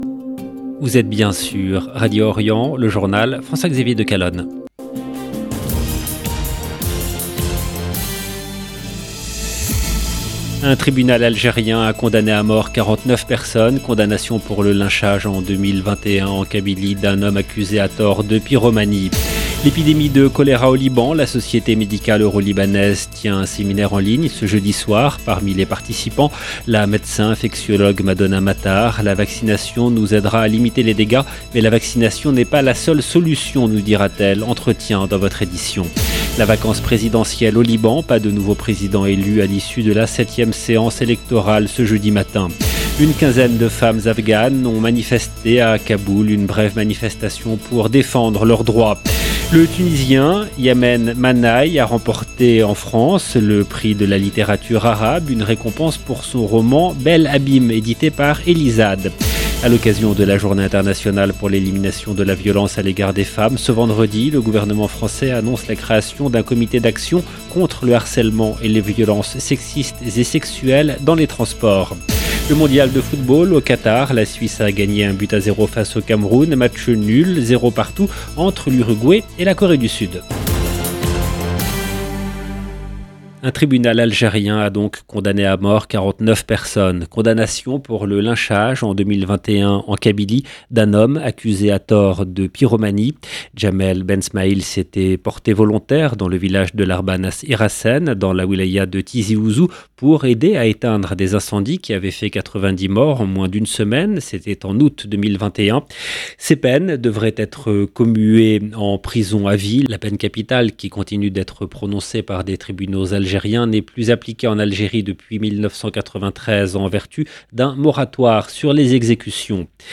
LE JOURNAL EN LANGUE FRANCAISE DU SOIR DU 24/11/22
Entretien dans votre journal. La vacance présidentielle au Liban.